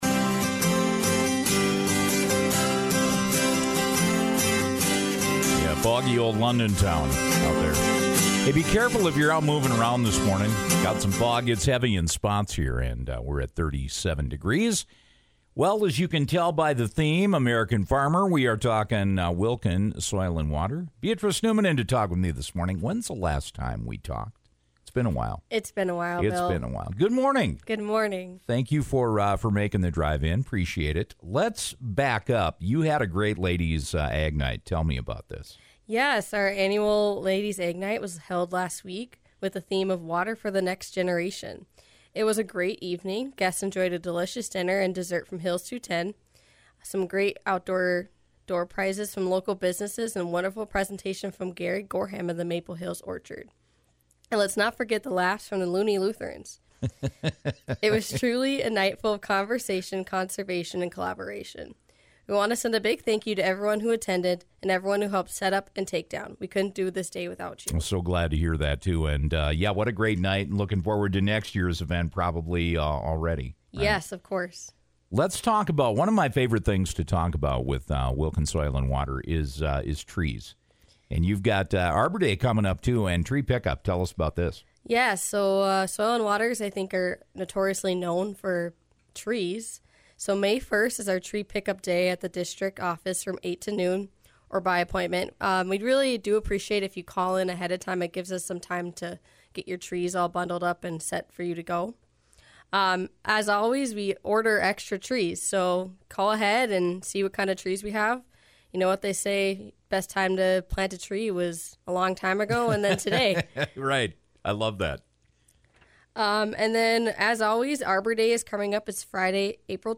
monthly chat